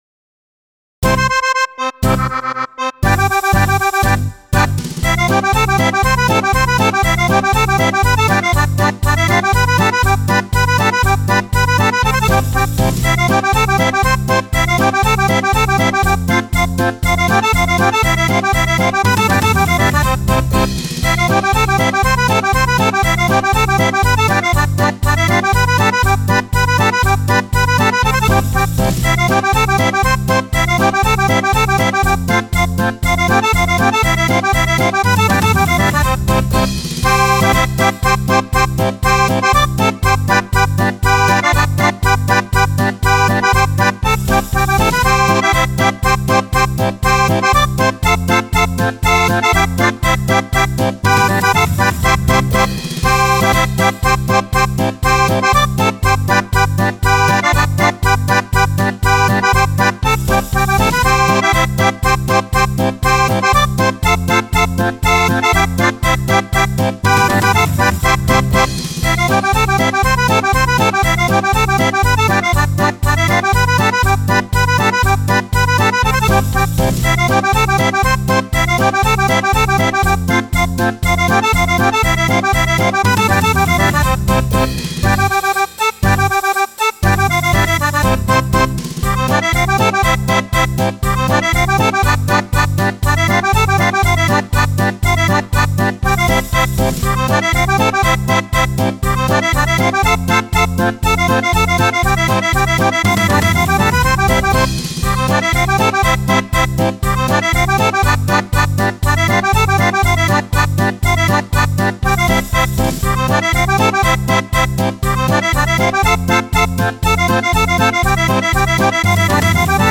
(DO) Polca
5 BRANI PER ORGANETTO  (2 e 4 Bassi)